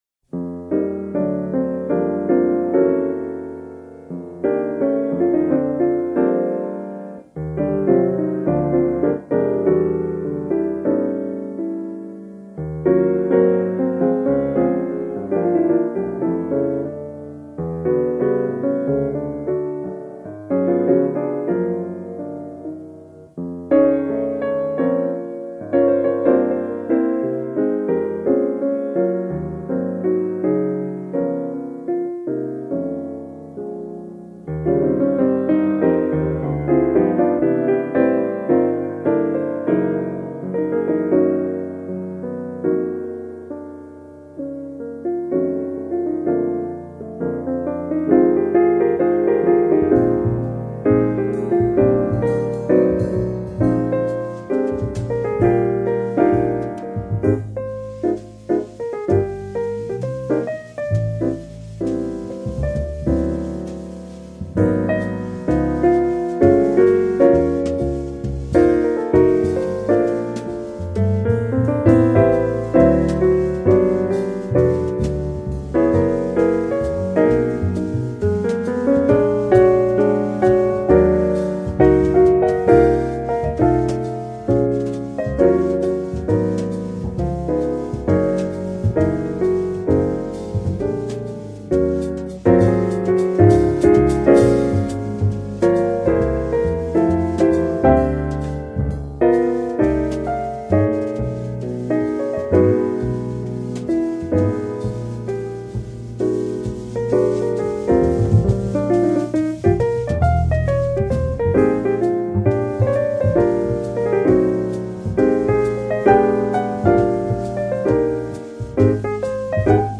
JAZZ PIANO SOLOS - AUDIO FOR SOLO TRANSCRIPTIONS